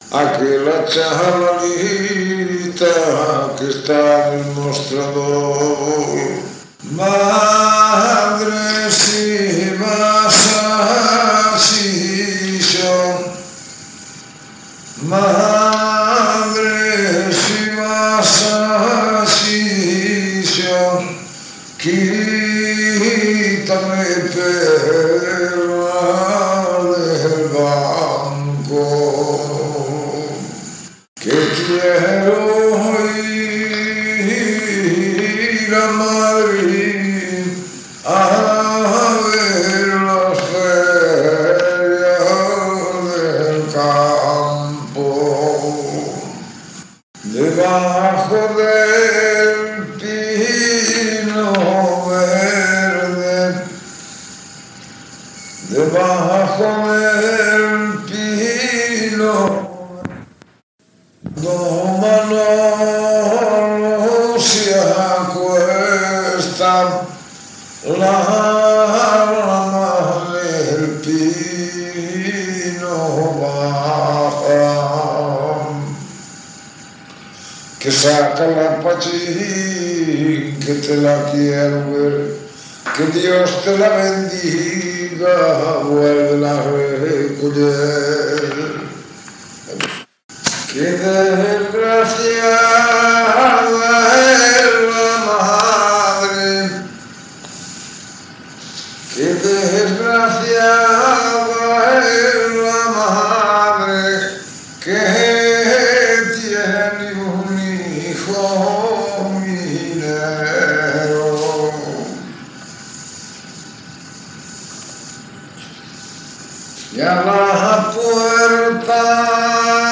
Coplas -
Tipo de rexistro: Musical
Lugar de compilación: Pol - Milleirós (Santiago)